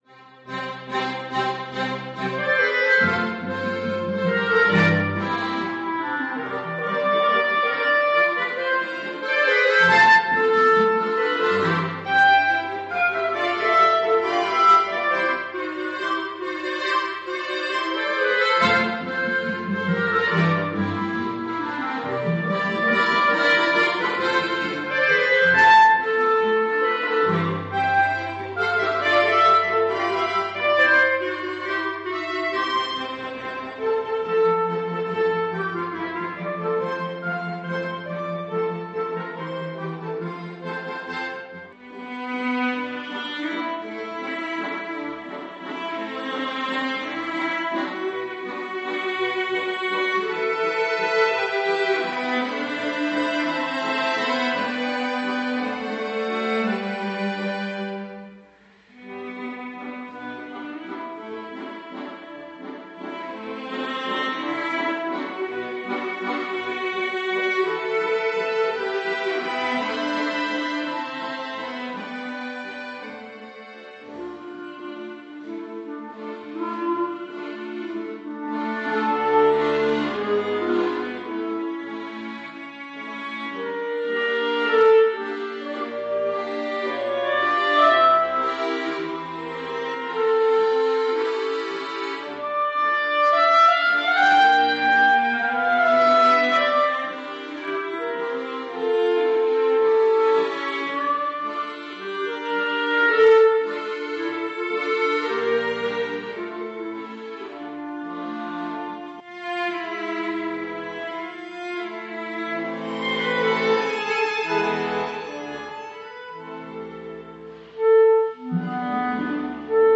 Neujahrskonzert in Reinach AG